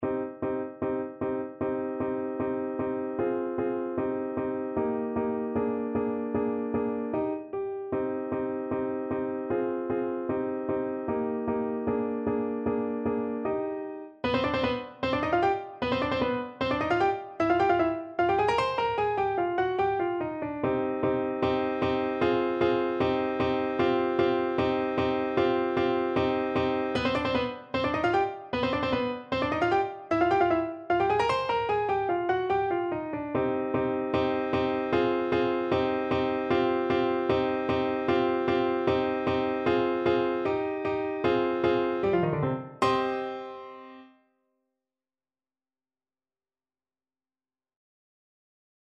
Play (or use space bar on your keyboard) Pause Music Playalong - Piano Accompaniment Playalong Band Accompaniment not yet available transpose reset tempo print settings full screen
C minor (Sounding Pitch) D minor (Trumpet in Bb) (View more C minor Music for Trumpet )
Allegro scherzando (=152) (View more music marked Allegro)
Classical (View more Classical Trumpet Music)